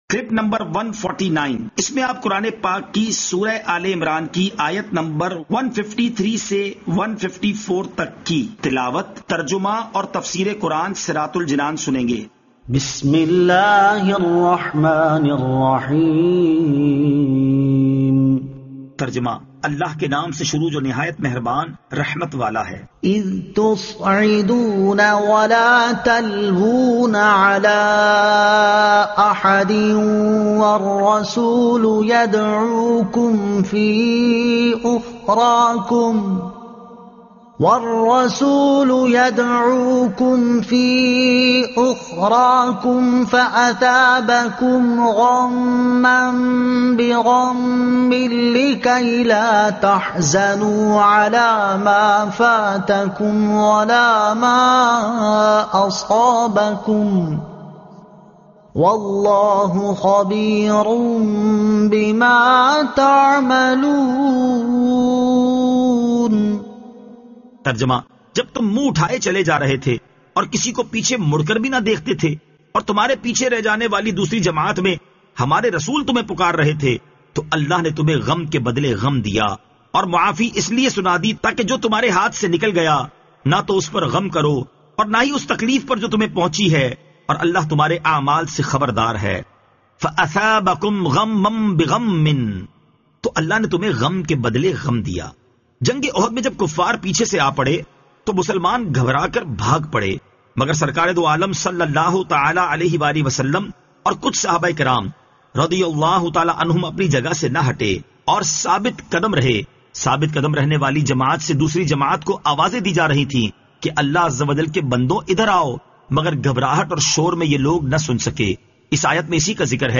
Surah Aal-e-Imran Ayat 153 To 154 Tilawat , Tarjuma , Tafseer